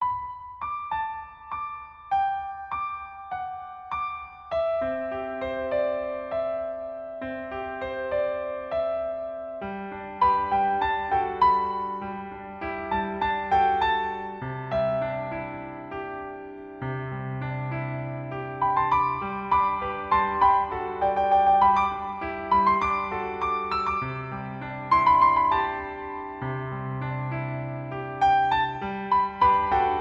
• 🎹 Instrument: Piano Solo
• 🎼 Key: G Major
• 🎶 Genre: Pop
heartfelt piano solo arrangement